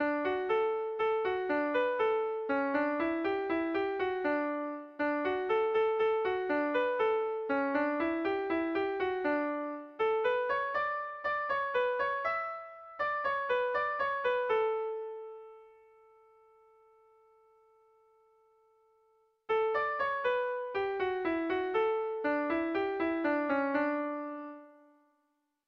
Bertso melodies - View details   To know more about this section
Kontakizunezkoa
Zortziko ertaina (hg) / Lau puntuko ertaina (ip)
AABD